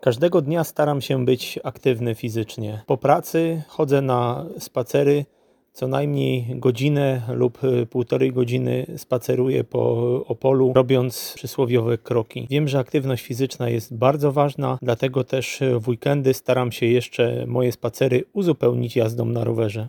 Mieszkańcy Opola opowiedzieli nam, jaką formę aktywności fizycznej preferują najbardziej: